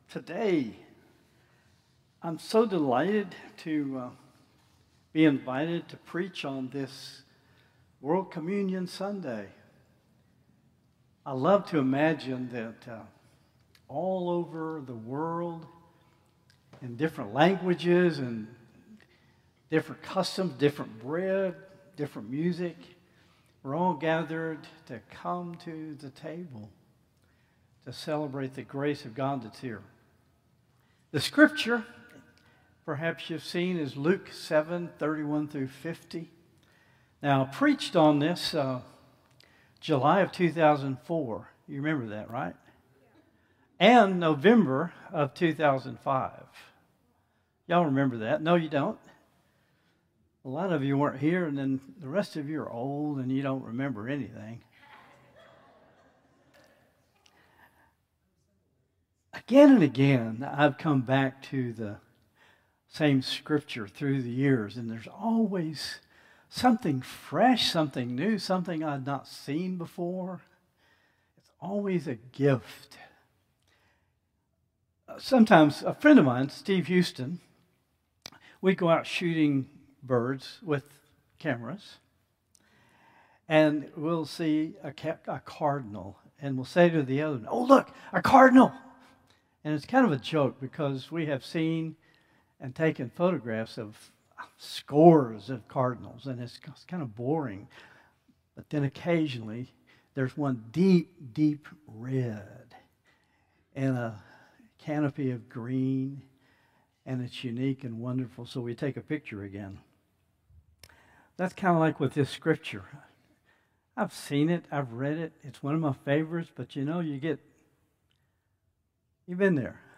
Traditional Service 10/5/2025